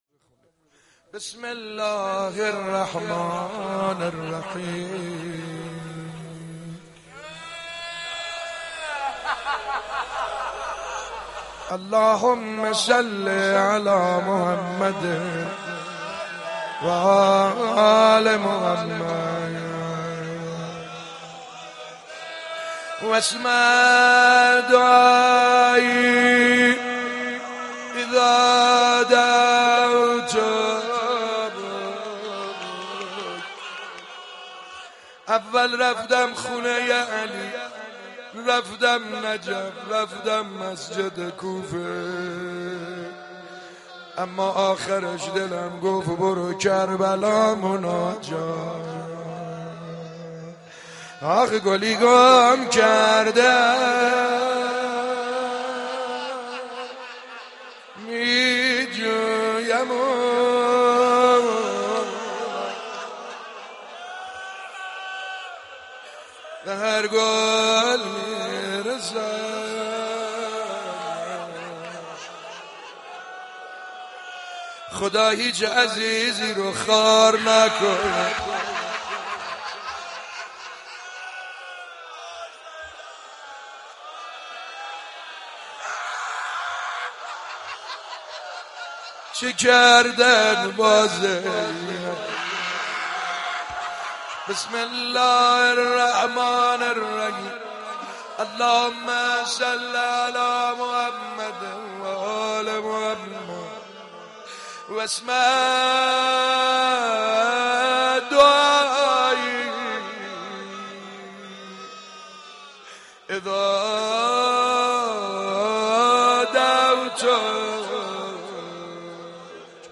«مناجات شعبانیه» گنجینه عظیم معارف الهی که همه ائمه به خواندن آن اهتمام داشته‌اند/ دانلود مناجات شعبانیه با نوای مداحان مختلف